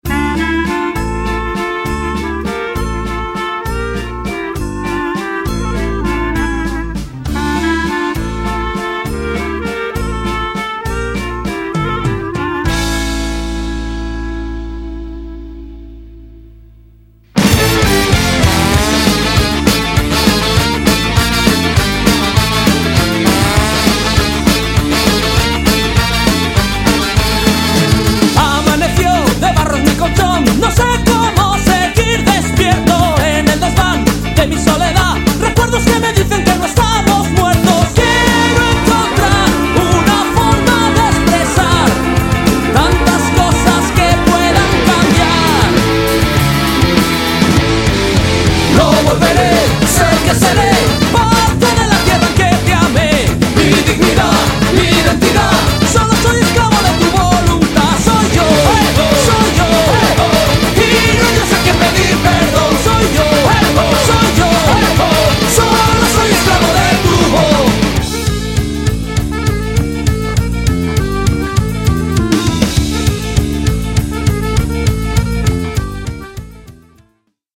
そのサウンドはストレートで全開、CDからも充分に伝わるパワーに熱くなることは必至！
そのエキゾチックなメロディが東欧ジプシー音楽風味を色漬け、新鮮さをプラスしている。